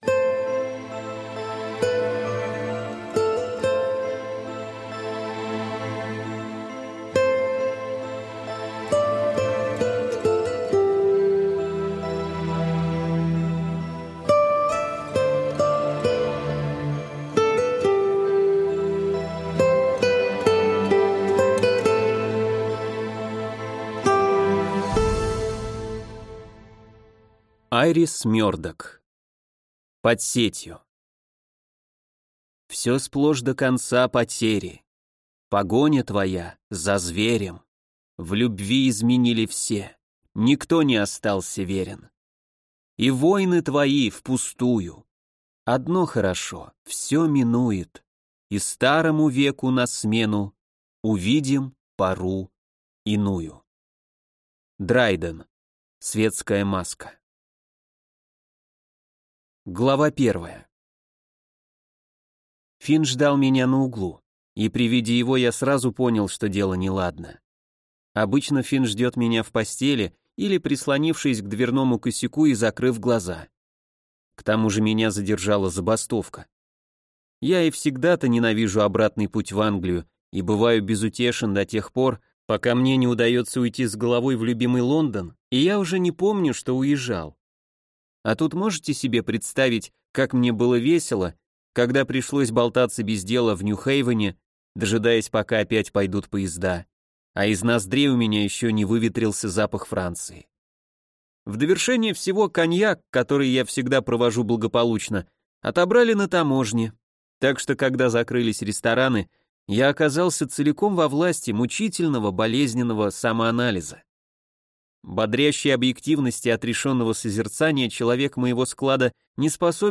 Аудиокнига Под сетью | Библиотека аудиокниг